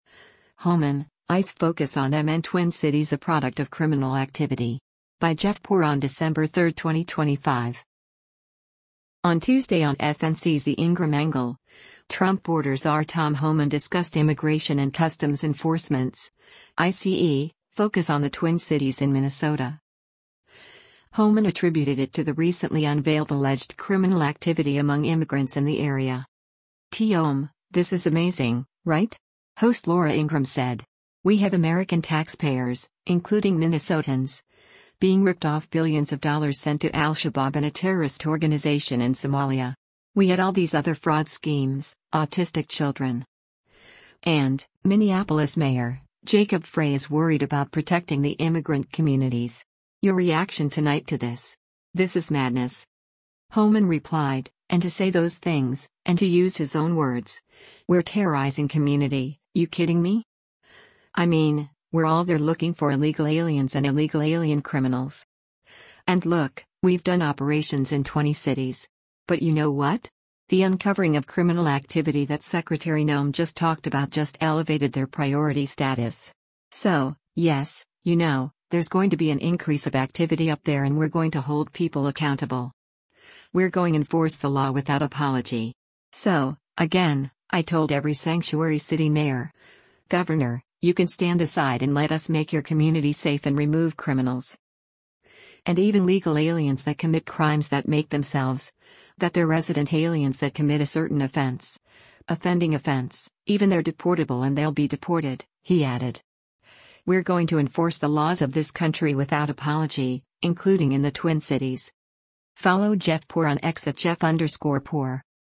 On Tuesday on FNC’s “The Ingraham Angle,” Trump border czar Tom Homan discussed Immigration and Customs Enforcement’s (ICE) focus on the Twin Cities in Minnesota.